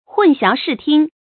hùn xiáo shì tīng
混淆视听发音
成语注音 ㄏㄨㄣˋ ㄒㄧㄠˊ ㄕㄧˋ ㄊㄧㄥ
成语正音 淆，不能读作“yáo”。